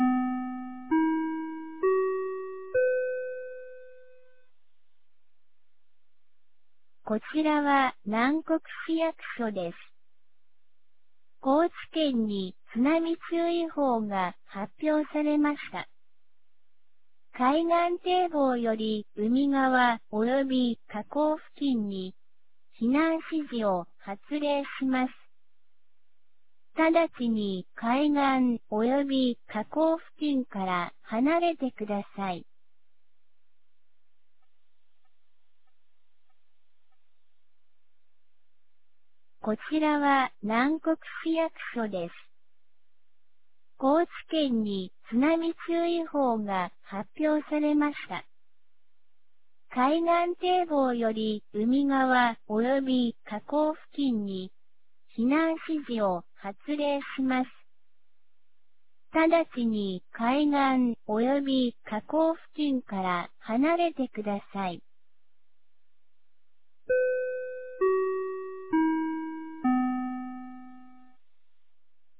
南国市放送内容
2025年01月13日 21時53分に、南国市より放送がありました。